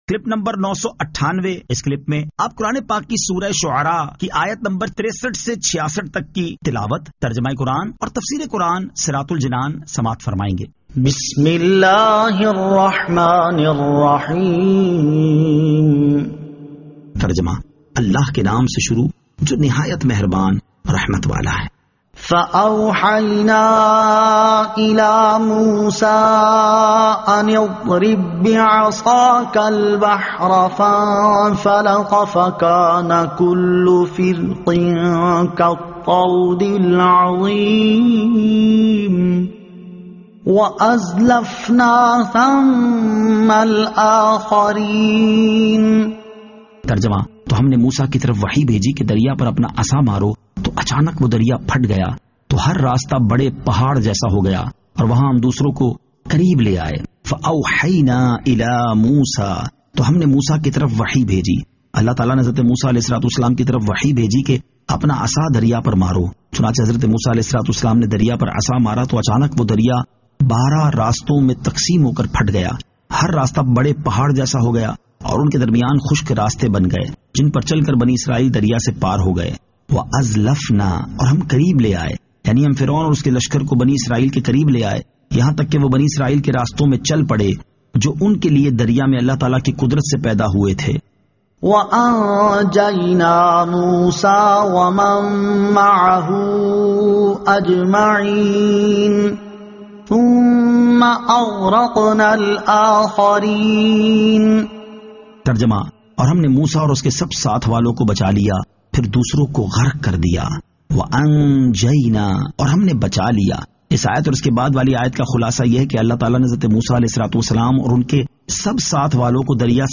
Surah Ash-Shu'ara 63 To 66 Tilawat , Tarjama , Tafseer